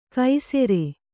♪ kai sere